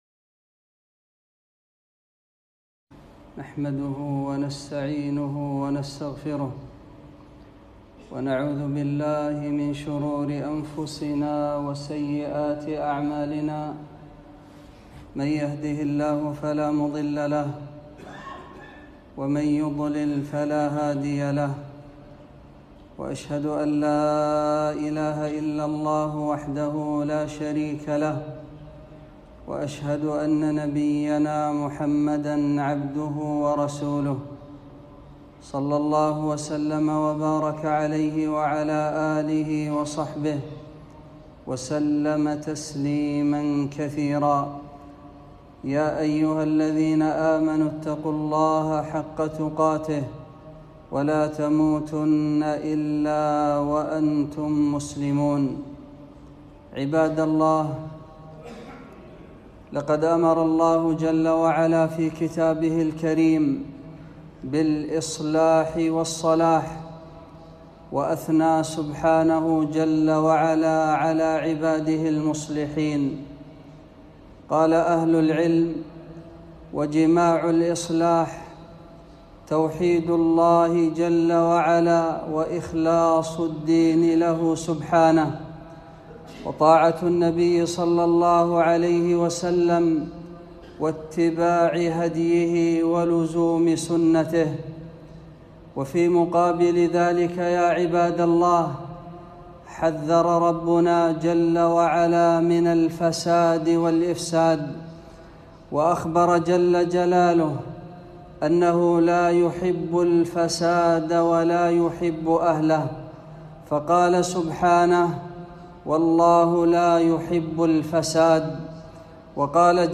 خطبة - ولا تفسدوا في الأرض بعد إصلاحها